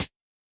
Index of /musicradar/ultimate-hihat-samples/Hits/ElectroHat D
UHH_ElectroHatD_Hit-31.wav